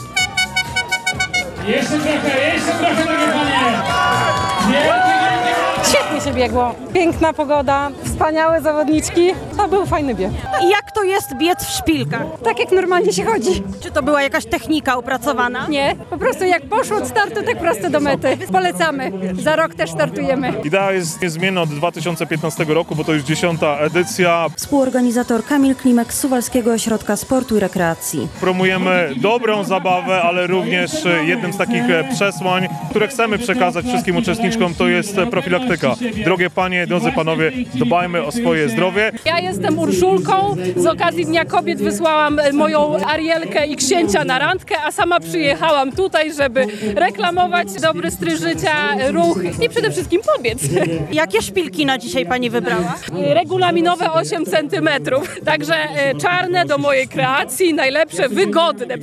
Dziesiąta edycja Miss Run w Suwałkach - relacja